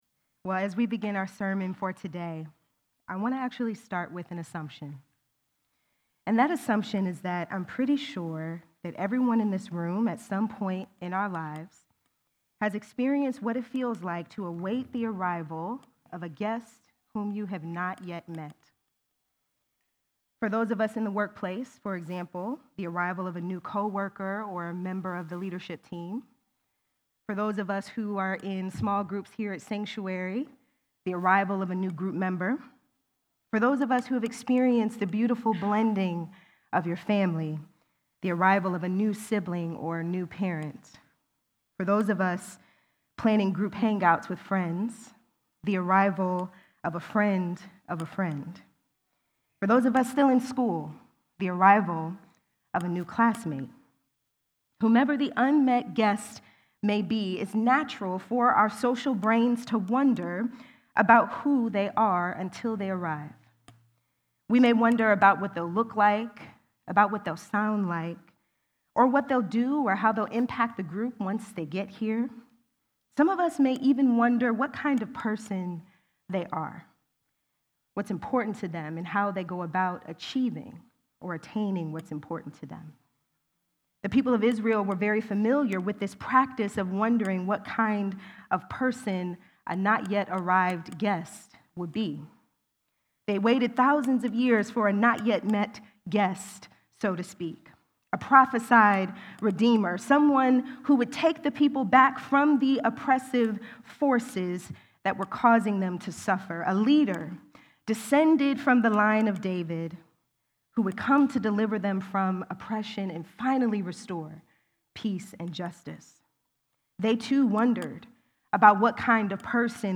Sermons | Sanctuary Columbus Church